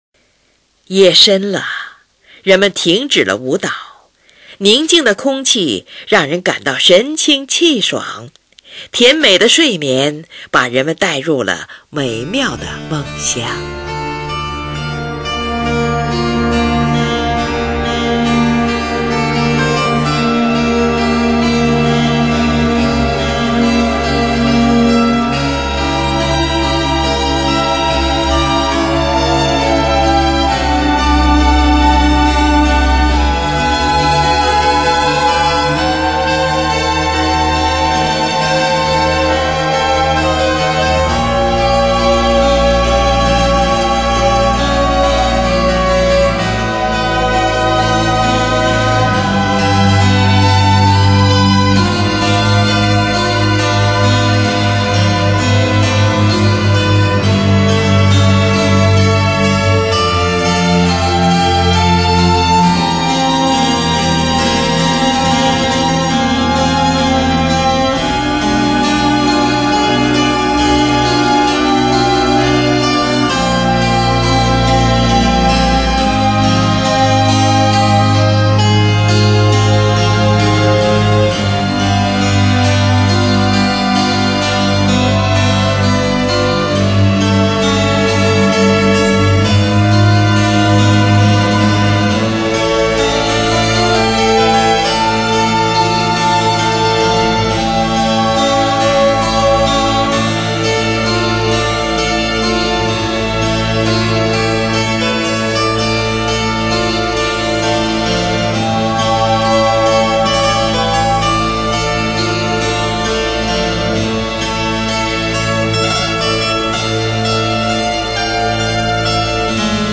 小提琴协奏曲
很慢板，D小调。